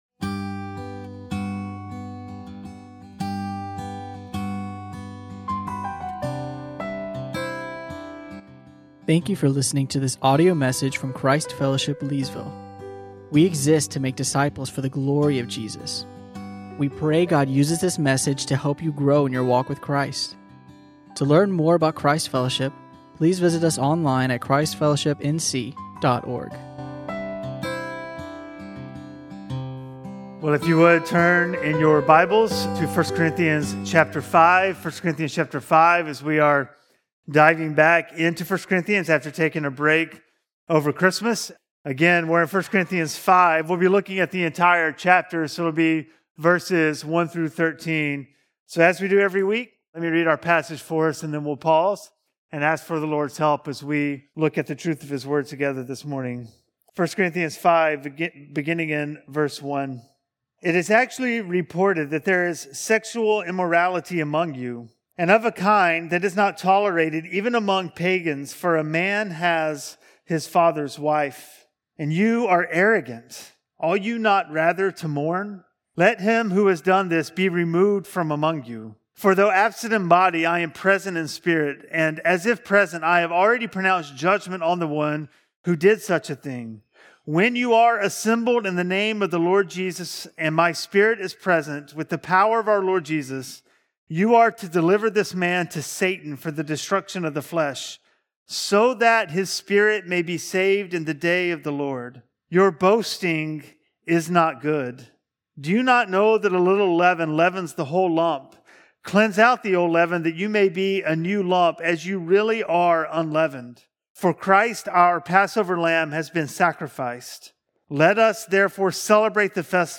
teaches on 1 Corinthians 5:1-13.